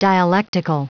Prononciation du mot dialectical en anglais (fichier audio)
Prononciation du mot : dialectical